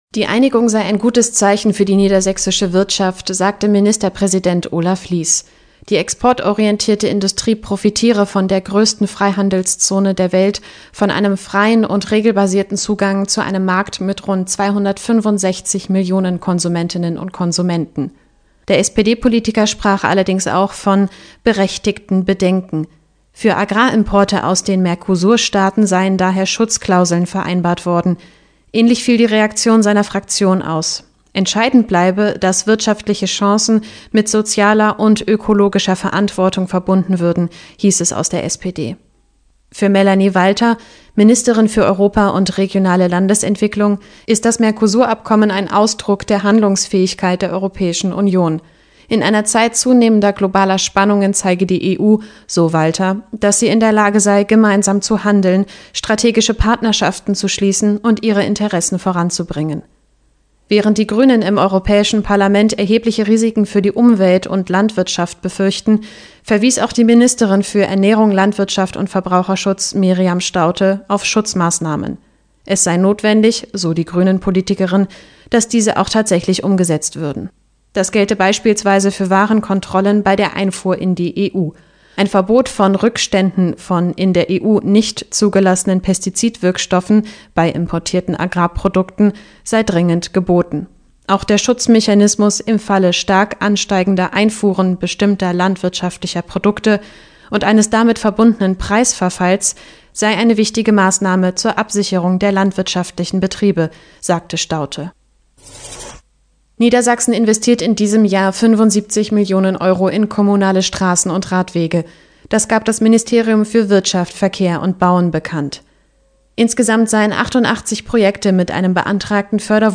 Die Reaktionen aus der niedersächsischen Politik fallen dagegen vorsichtig optimistisch aus. Einzelheiten dazu und weitere Meldungen aus unserem Bundesland jetzt